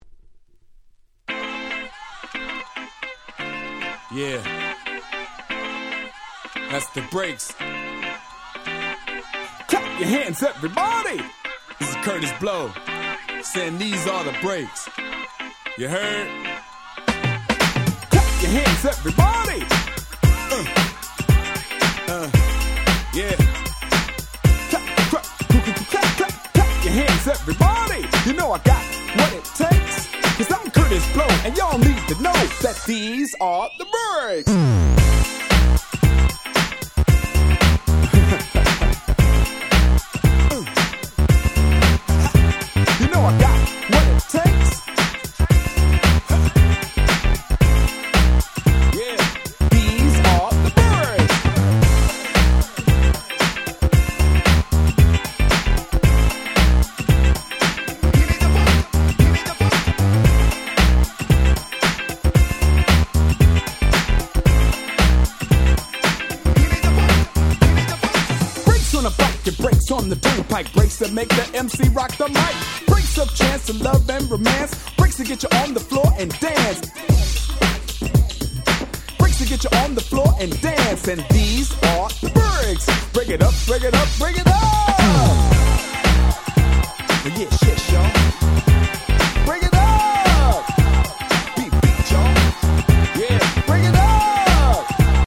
02' Nice Break Beats !!